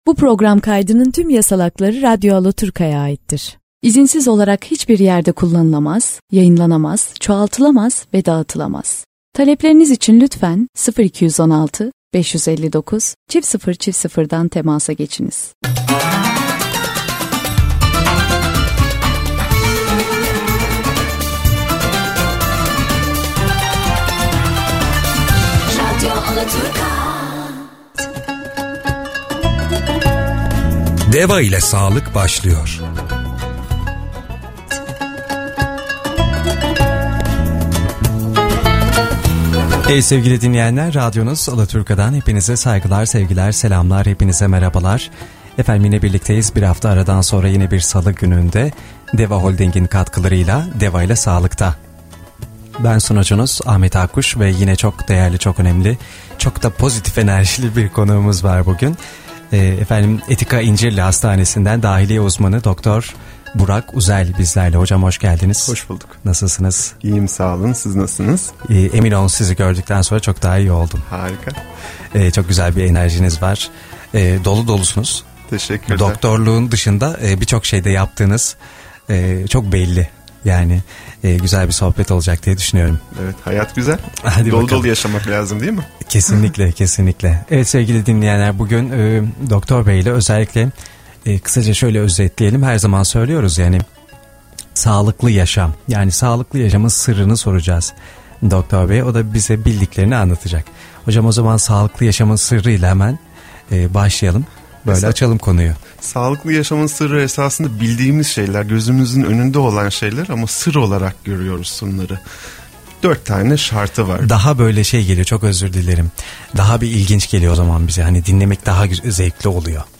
20 Temmuz’da canlı yayının kaydını dinlemek için aşağıdaki düğmeye basın